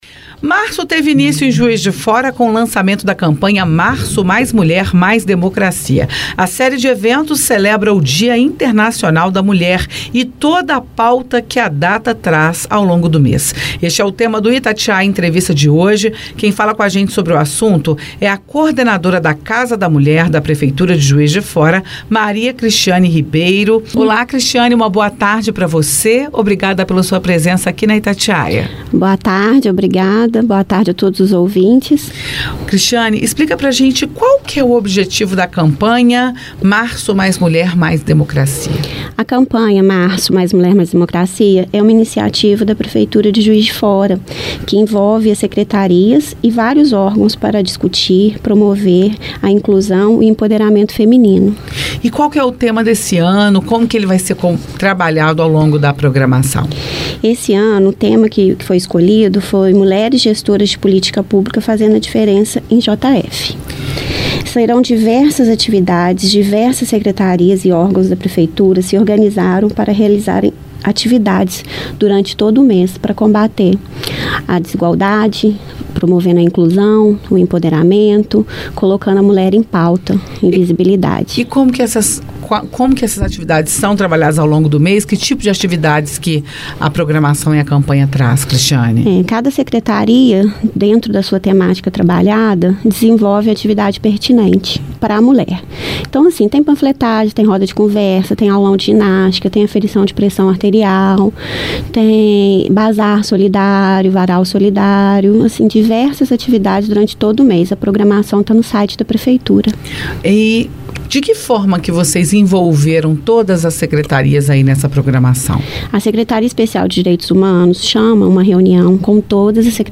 Itatiaia-Entrevista-Marco-Mais-Mulher-Mais-Democracia.mp3